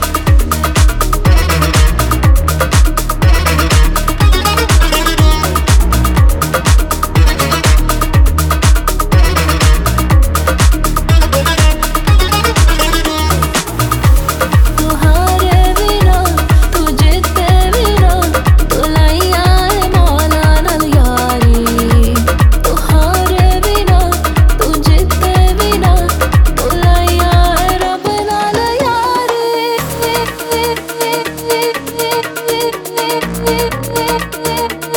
Afro-Pop African Dance
Жанр: Поп музыка / Танцевальные